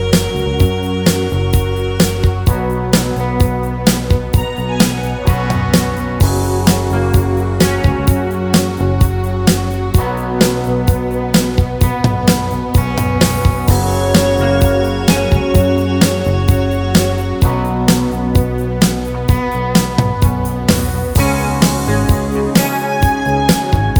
Minus Main Guitar Pop (1970s) 4:27 Buy £1.50